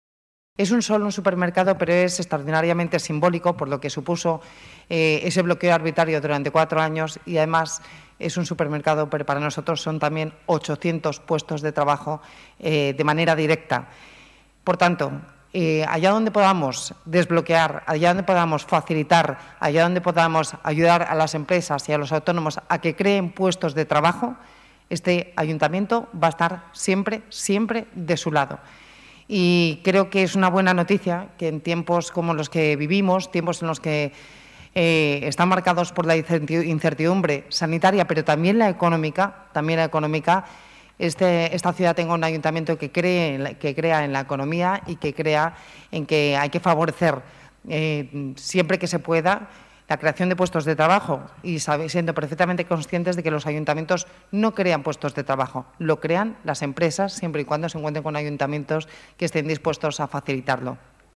Nueva ventana:Declaraciones de Begoña Villacís, vicealcaldesa de Madrid, sobre el supermercado de proximidad en el Paseo de Delicias